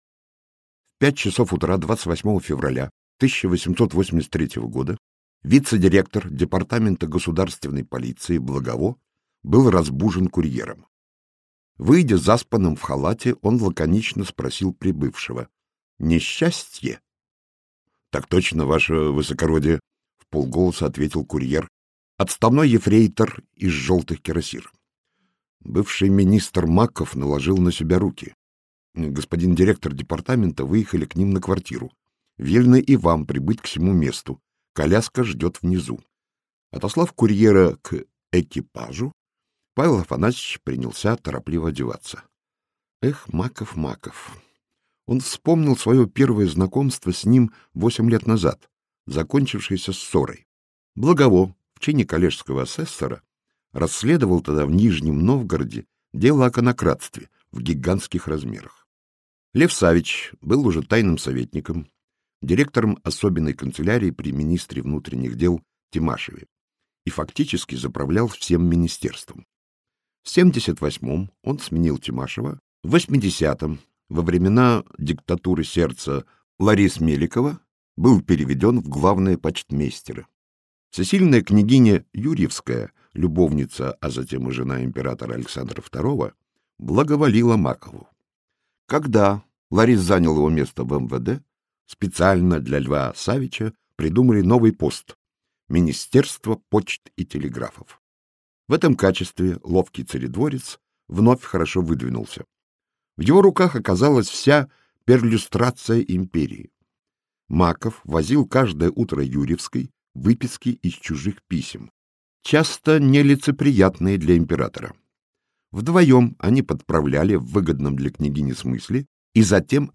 Аудиокнига Выстрел на Большой Морской - купить, скачать и слушать онлайн | КнигоПоиск